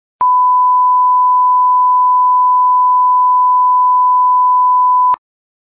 Censor Bleep Sound Effect
Category: Sound FX   Right: Personal